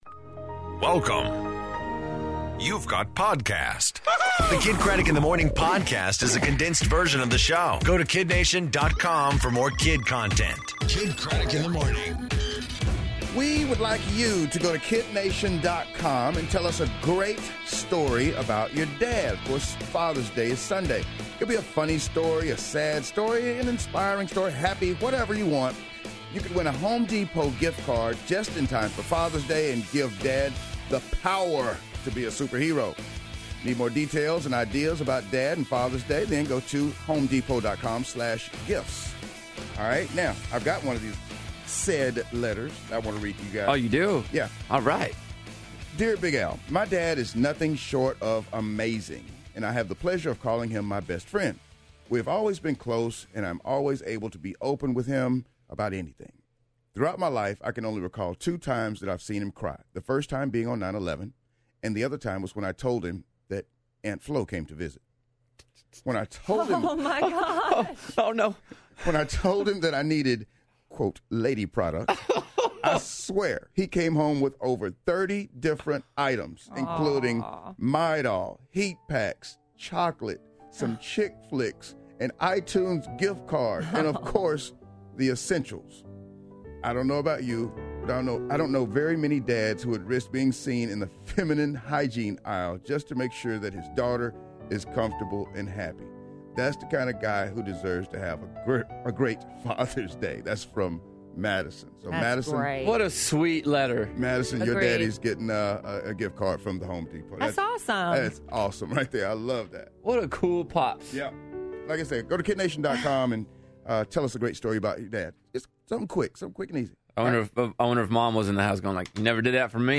Does That Make My Dad Crazy!? Soap Opera Plots, And ZZ Ward Performs